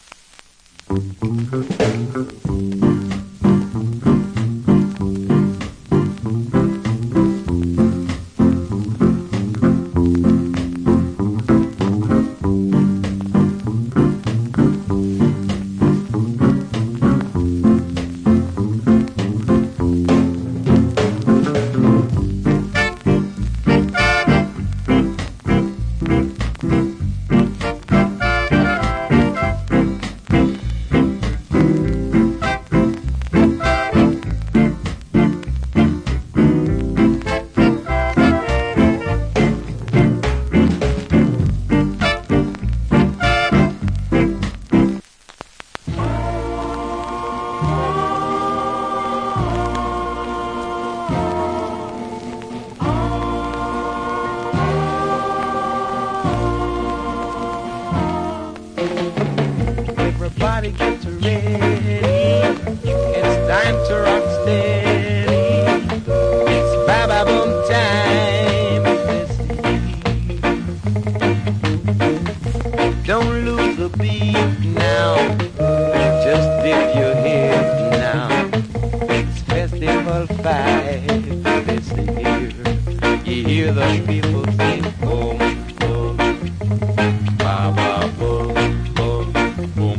Great Rock Steady Inst.